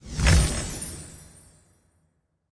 victory_reward_collect.ogg